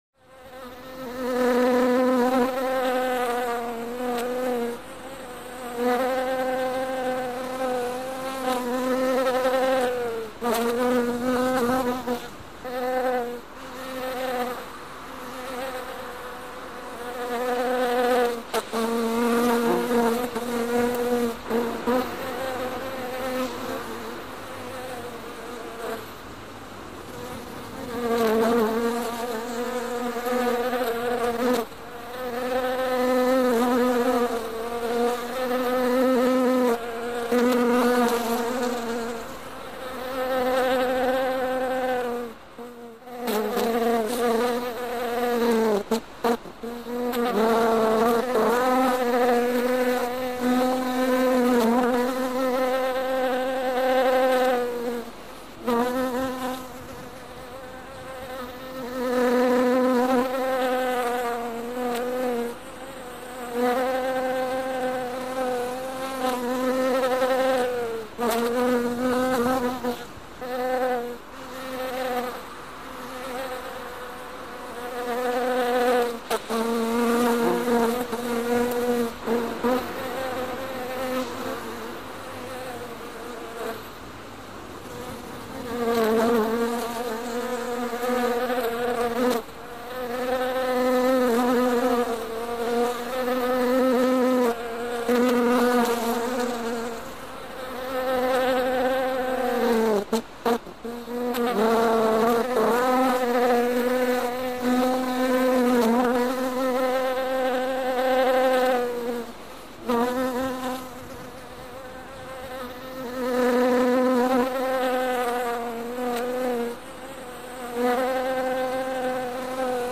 bees